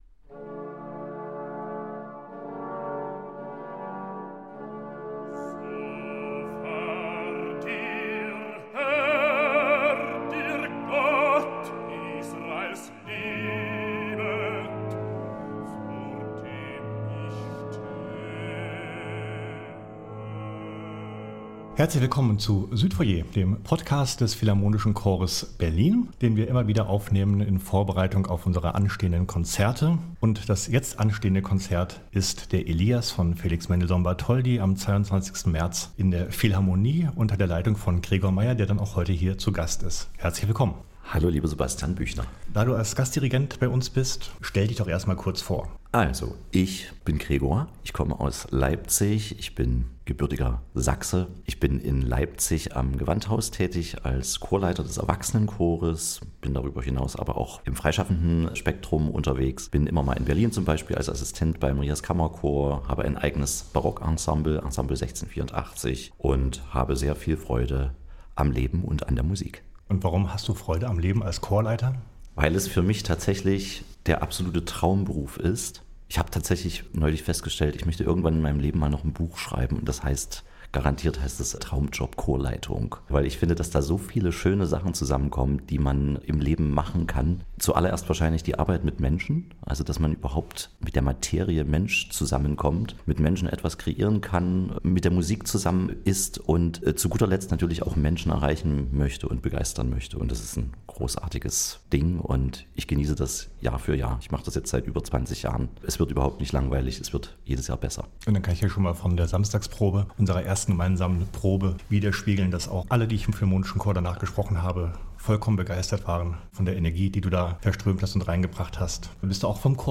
Podcastgespräch